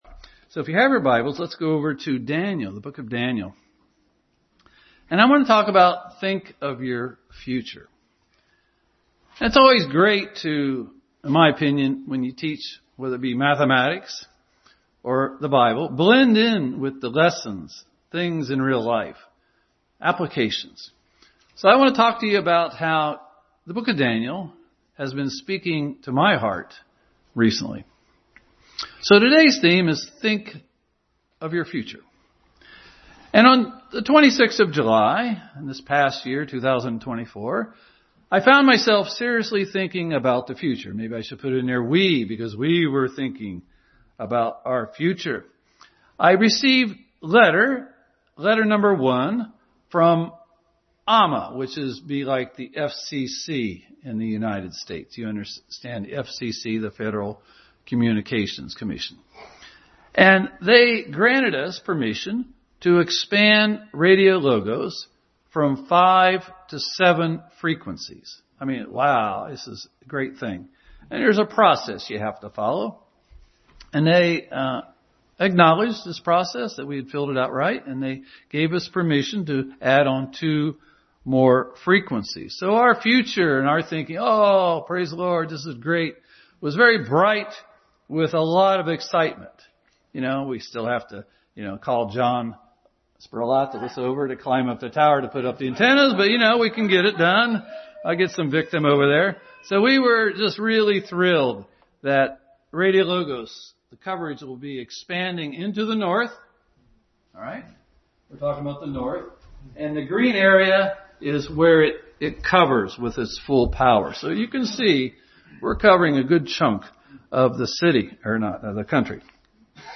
Think of Your Future Passage: Daniel 1:1-8, 9, 20, 6:7-10, 28, Genesis 13:11, 19:1, 2 Peter 2:7, 1 Timothy 6:10, 1 Samuel 2:30 Service Type: Family Bible Hour